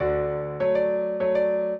piano
minuet0-10.wav